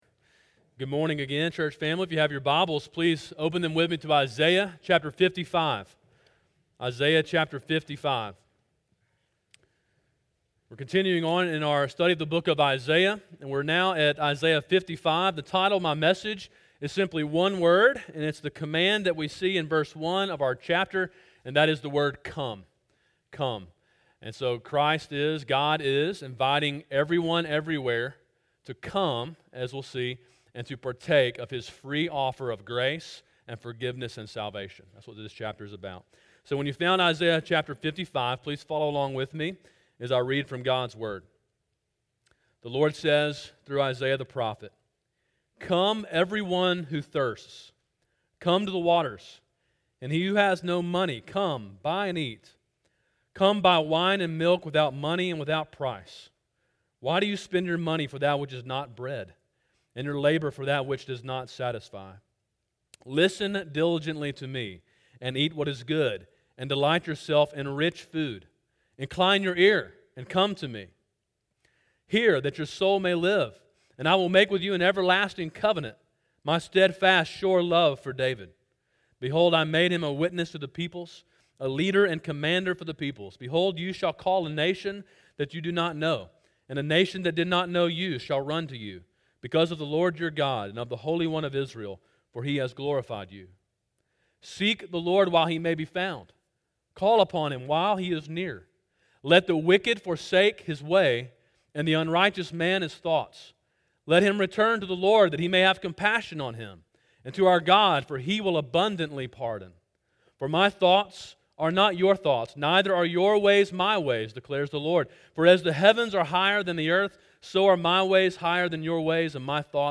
Sermon: “Come!” (Isaiah 55)